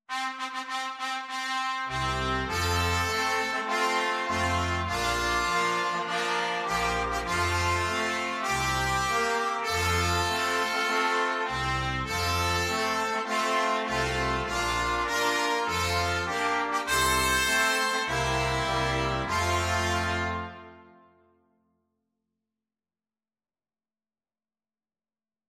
Free Sheet music for Brass Quintet
Trumpet 1Trumpet 2French HornTromboneTuba
Traditional Music of unknown author.
F major (Sounding Pitch) (View more F major Music for Brass Quintet )
With gusto!
4/4 (View more 4/4 Music)
Traditional (View more Traditional Brass Quintet Music)